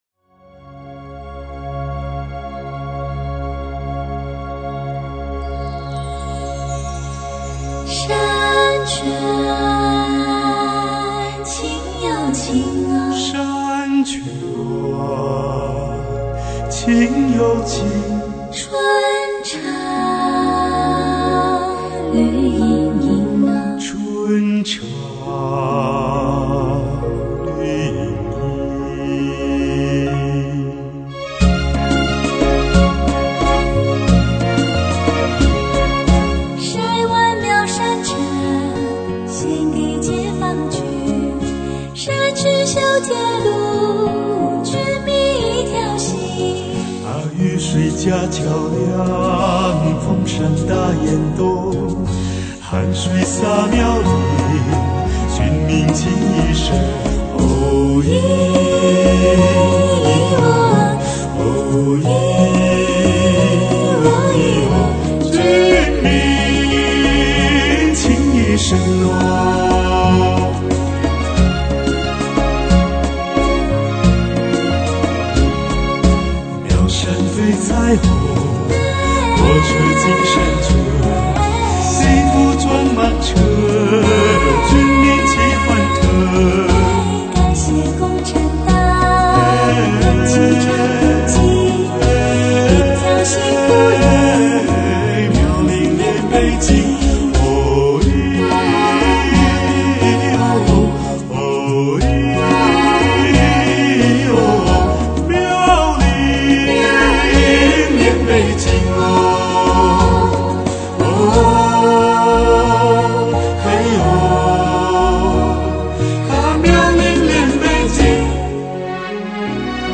三副纯净和谐的嗓子音韵珠玑，气象万千，极致的灵秀。
"三重唱"